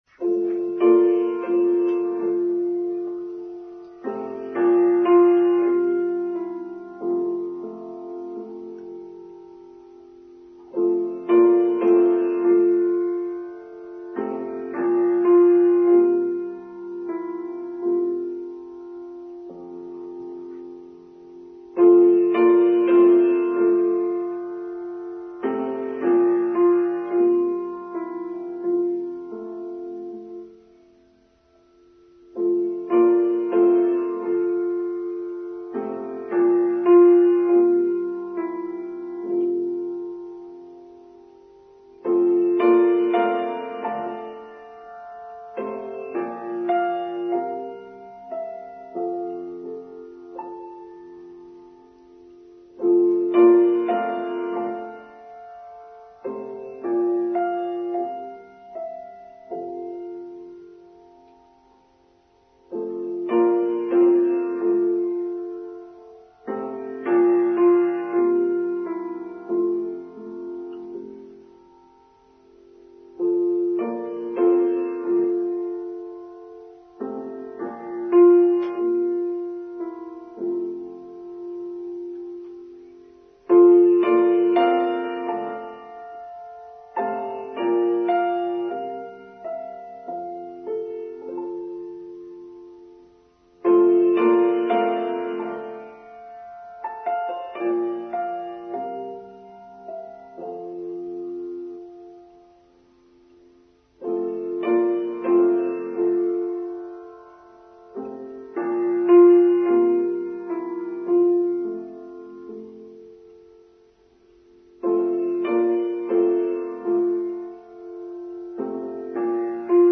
Liminal Space: Online Service for Sunday 14th May 2023